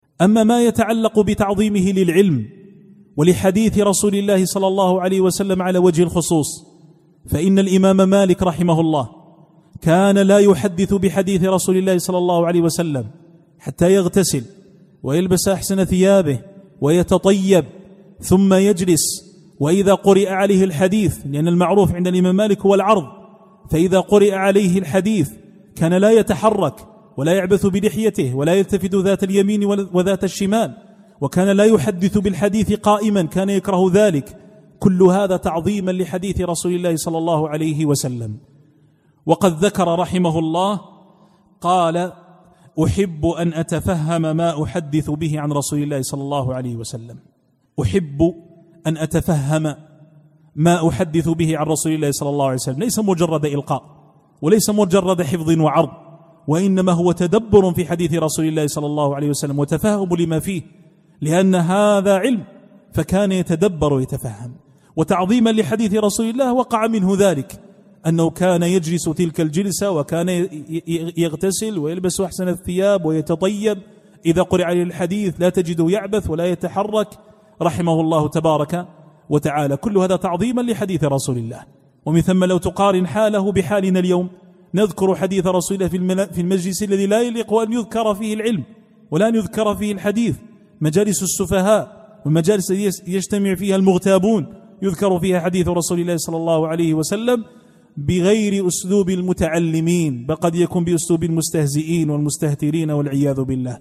الإستماع - التحميل  الدرس الرابع